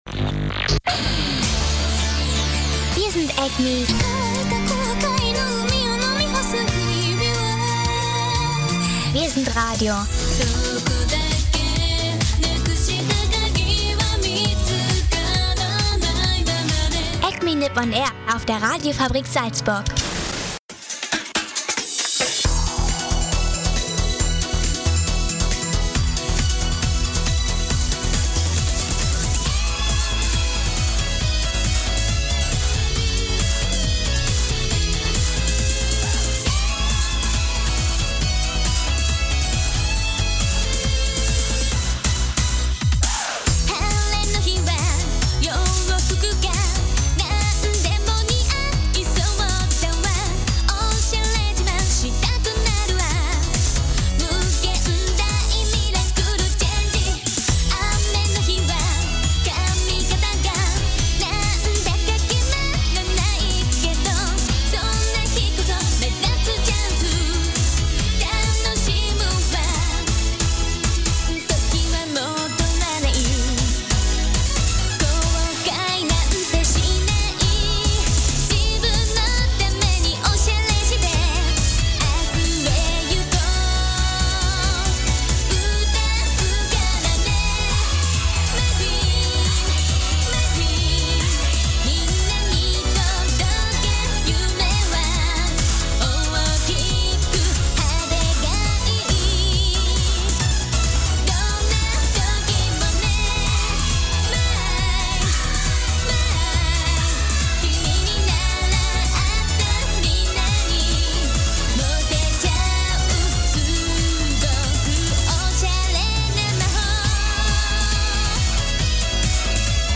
Diese Woche gibt es nur weibliche Interpreten in der Show, der Grund dafür dürfte naheliegend sein. Aber nicht nur das, auch über alte Animes von vor dem zweiten Weltkrieg wird berichtet.